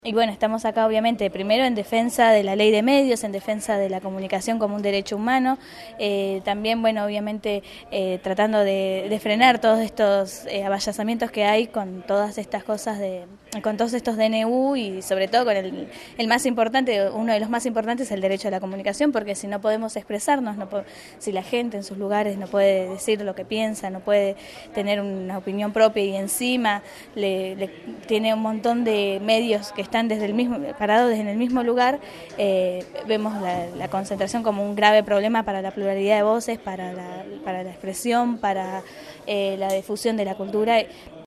Testimonios desde el Primer Congreso Nacional de la Coalición por una Comunicación Democrática